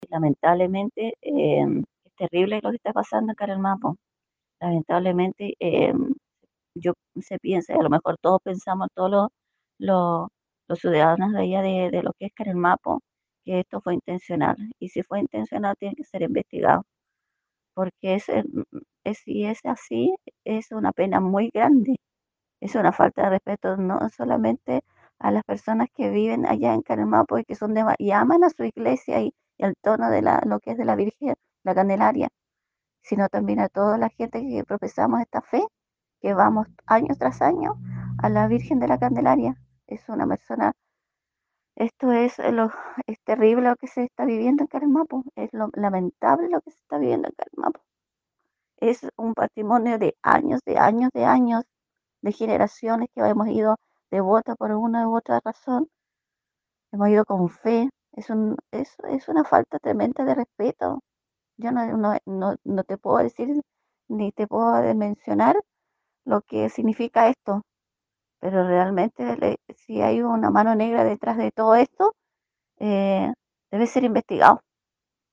El siniestro causó gran angustia y tristeza en la comunidad, como lo evidenció el testimonio de una visitante constante del histórico templo.